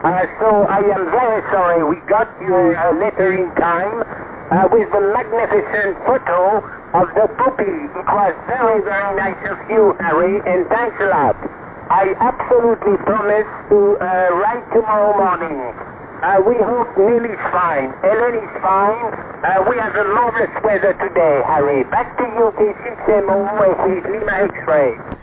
on 20 Meter SSB Longpath from California!